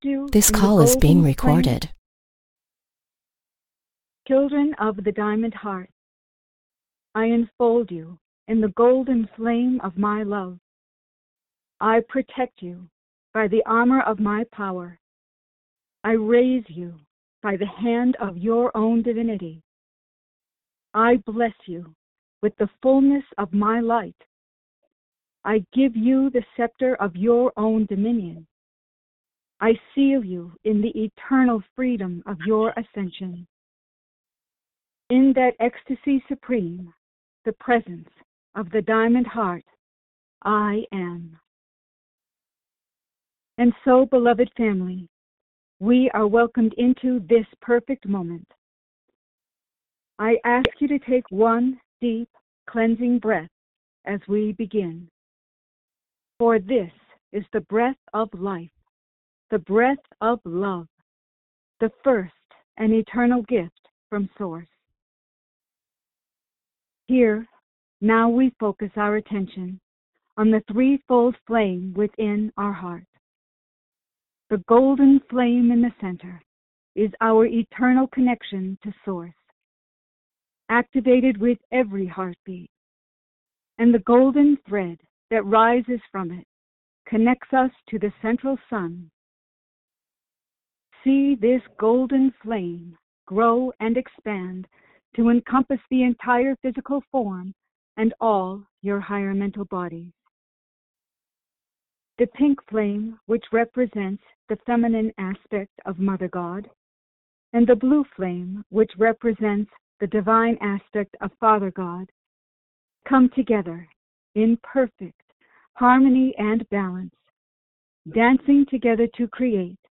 Meditation
Channeling
Questions & Answers